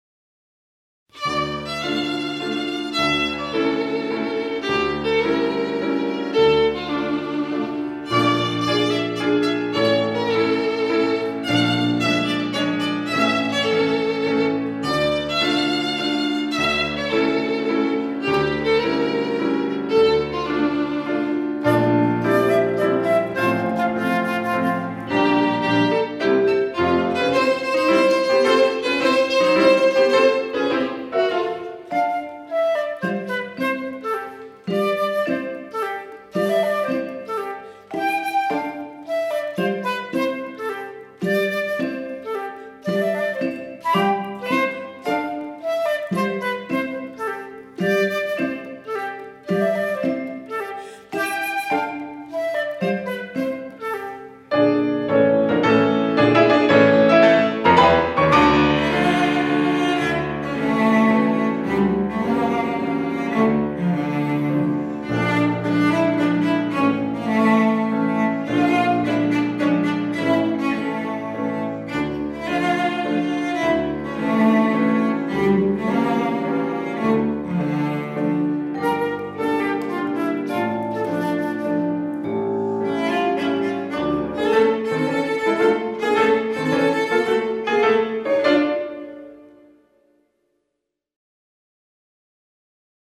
Жанр: Classical